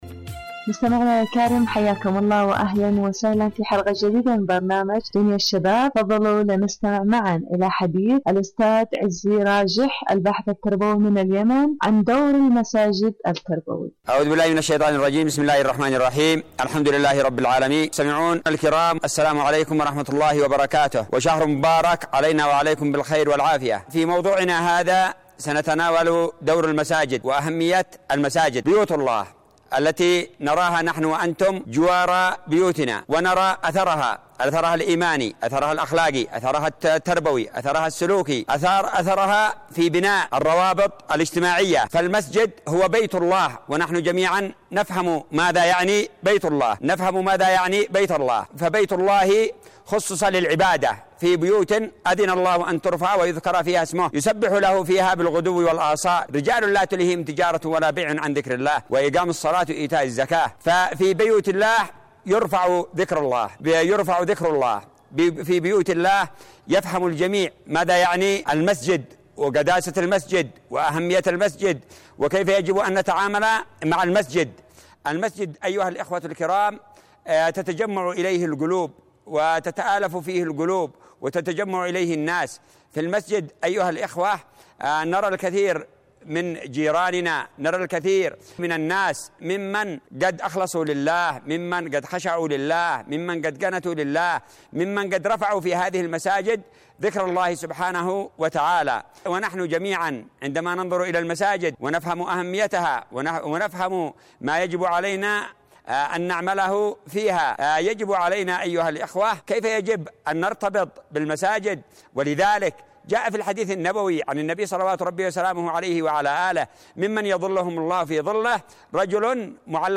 إذاعة طهران- دنيا الشباب: مقابلة إذاعية